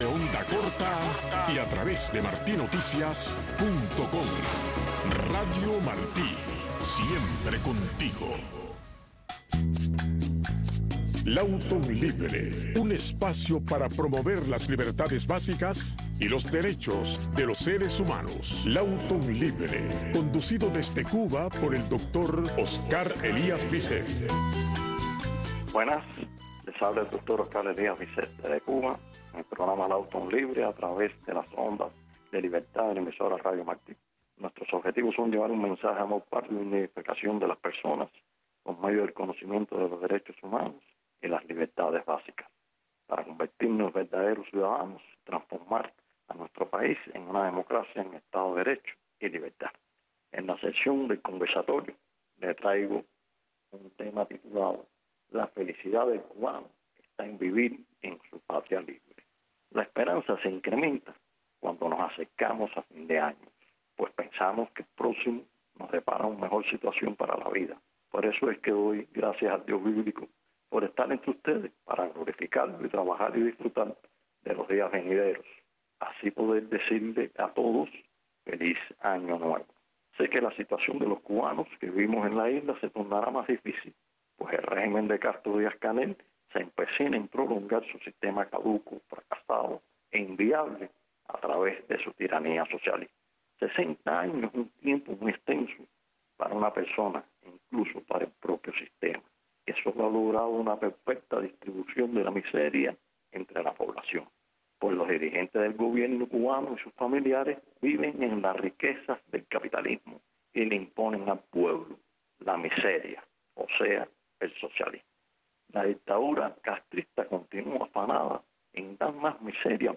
Lawton Libre es el programa conducido por el Doctor Oscar Elías Biscet que te habla de los derechos humanos, de las libertades básicas y de cómo lograr la libertad, tu libertad, porque si aprendes a ser libre todos los seremos Todos los sábados a las 7 am y también los sábados y domingos a las 11 de la noche en Radio Martí.